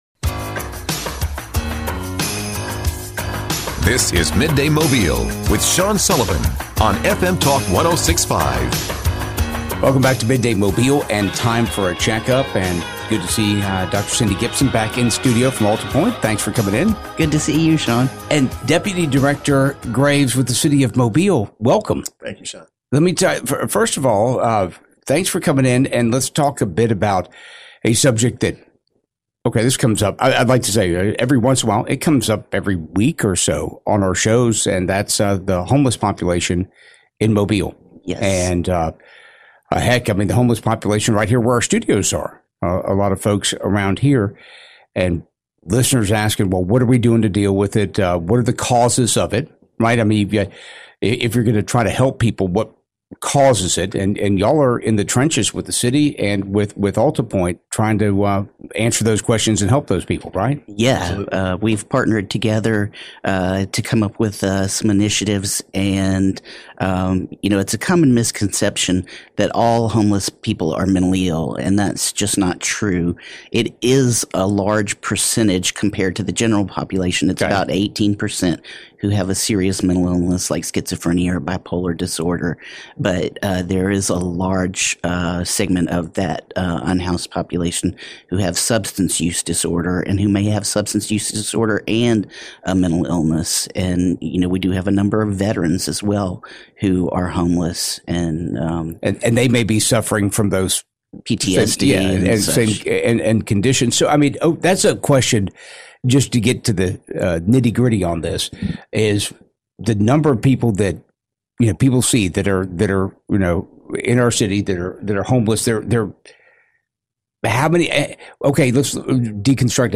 They talk about the connection between homelessness and mental issues. They discuss the different levels and the the different treatments. The city is working with AltaPoint Health to work to get help for the population. Listen to their conversation here: